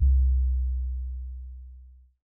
MB Kick (17).wav